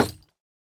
Minecraft Version Minecraft Version latest Latest Release | Latest Snapshot latest / assets / minecraft / sounds / block / bamboo_wood_hanging_sign / break3.ogg Compare With Compare With Latest Release | Latest Snapshot
break3.ogg